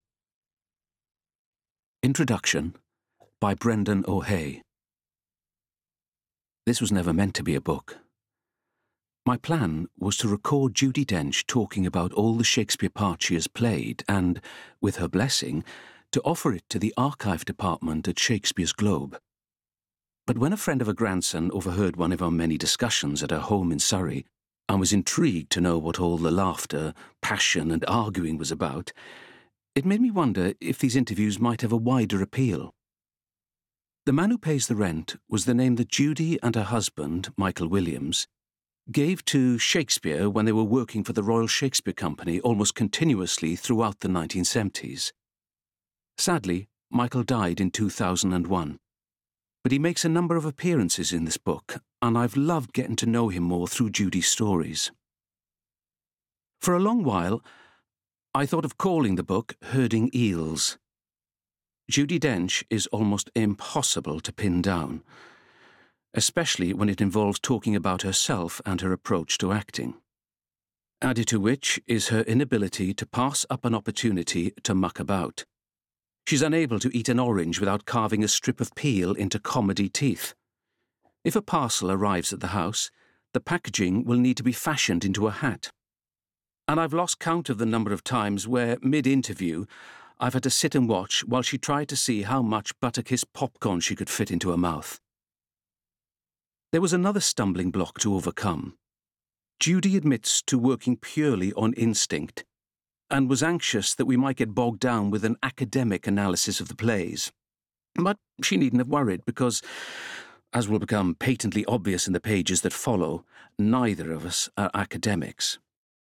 Audiobook Narration - Shakespeare - The Man Who Pays the Rent